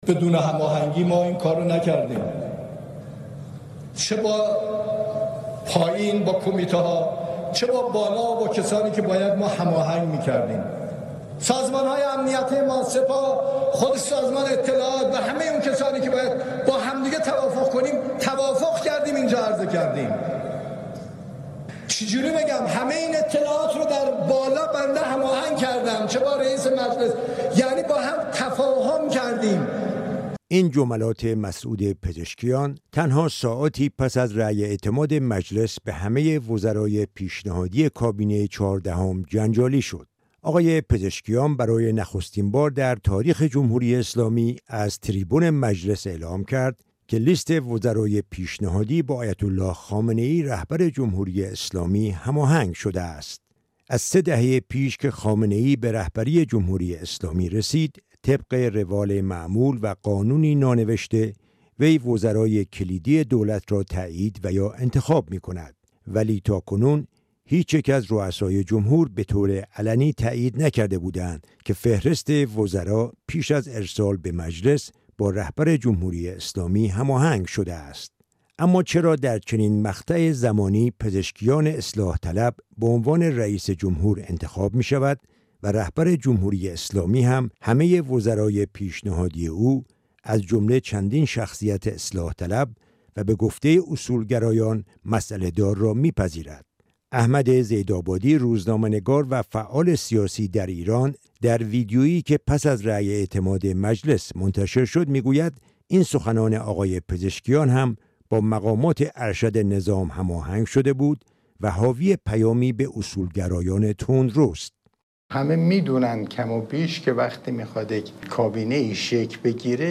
گزارش رادیویی از واکنش‌ها به اظهارات پزشکیان در مجلس درباره کابینه